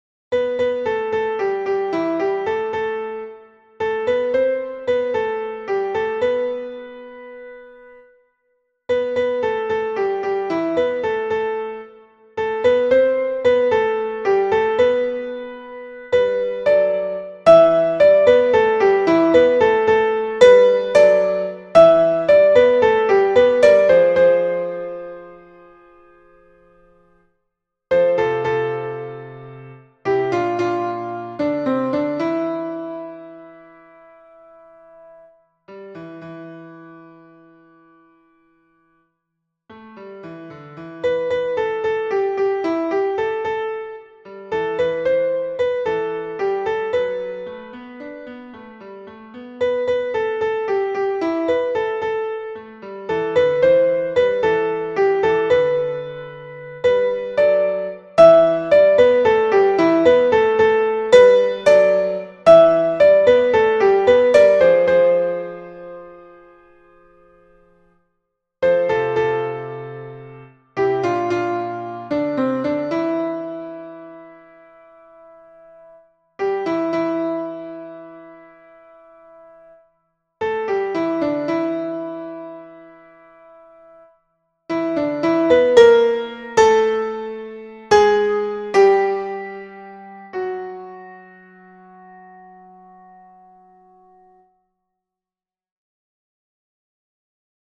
Soprano et autres voix en arrière-plan